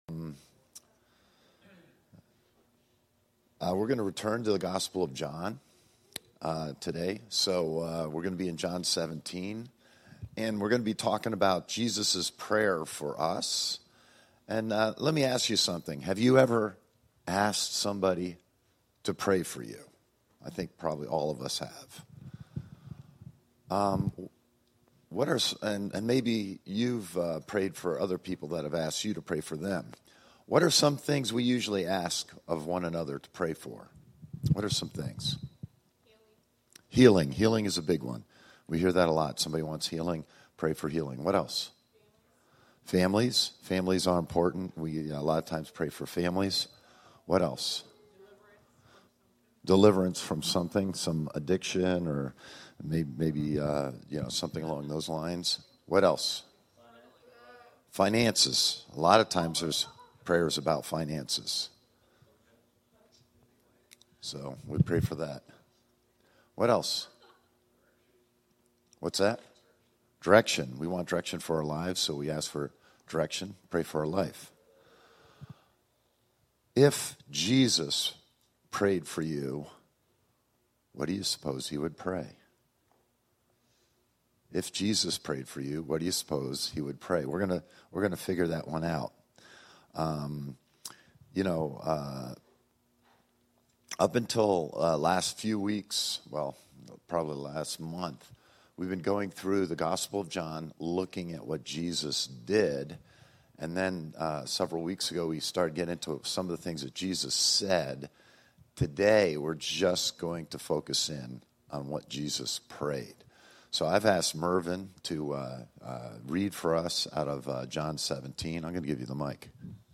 A message from the series "Sunday Service."
This is the main Sunday Service for Christ Connection Church